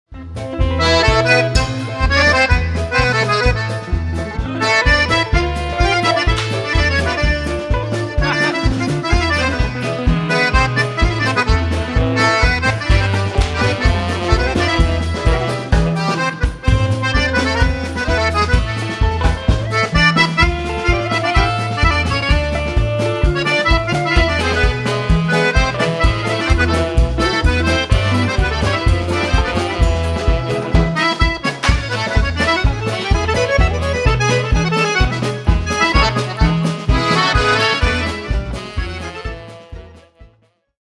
paso